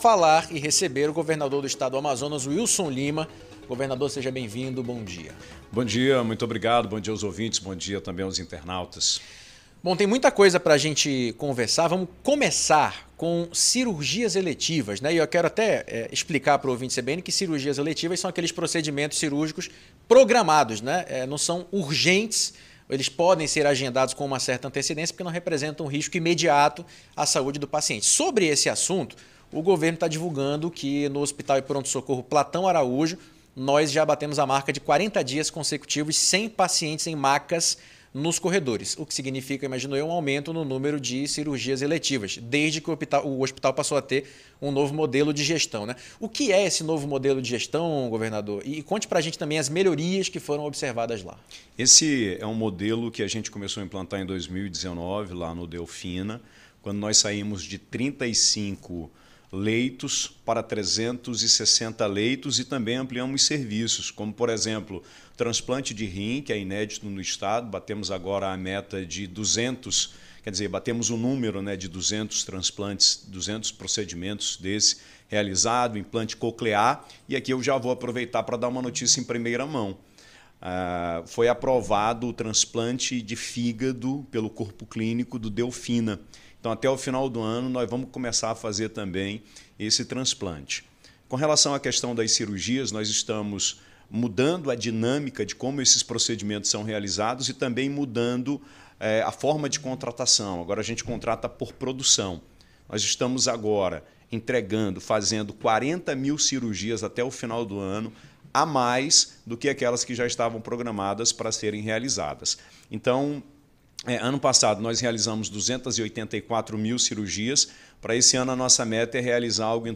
Saúde, saneamento básico e candidatura ao Senado: ouça entrevista completa com Governador do Amazonas, Wilson Lima.
A entrevista foi concedida ao CBN Jornal da Manhã, nesta quinta-feira (21).
ENTREVISTA-WILSON-LIMA.mp3